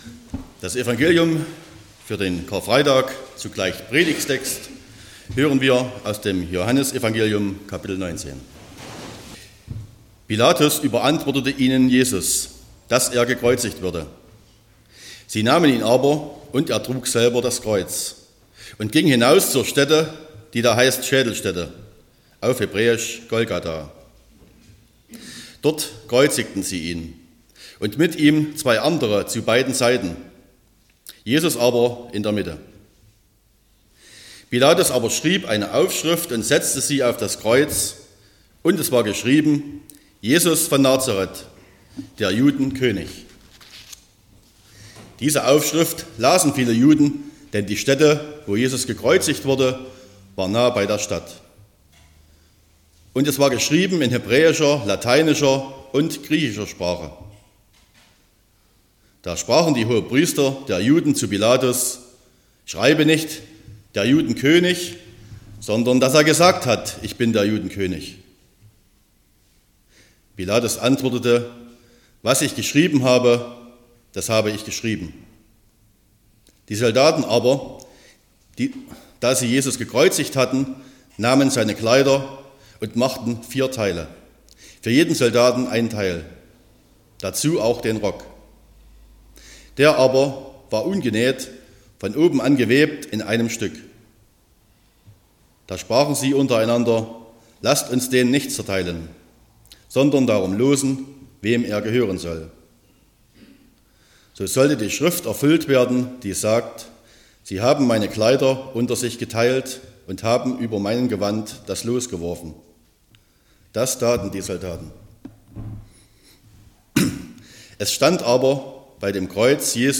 18.04.2025 – Gottesdienst
Predigt (Audio): 2025-04-18_Vollbracht_-_es_ist_an_alles_gedacht_.mp3 (20,9 MB)